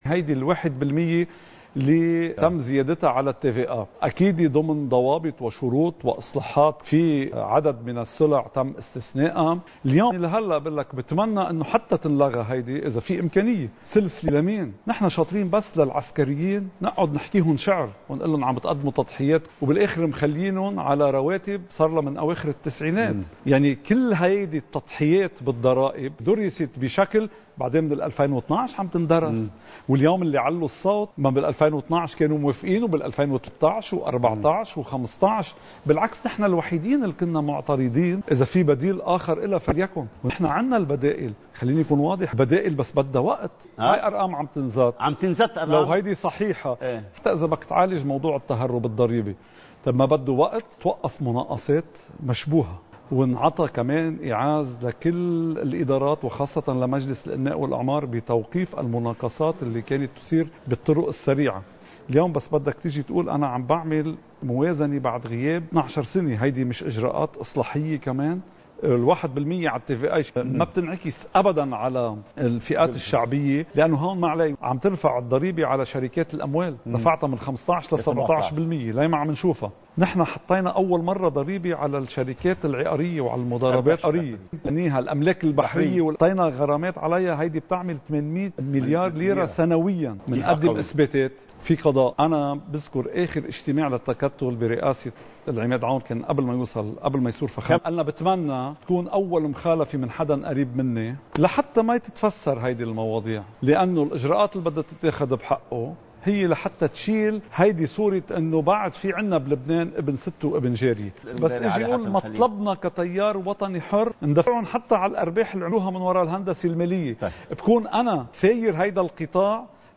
مقتطف من حديث النائب السابق سليم عون لقناة “المنار”: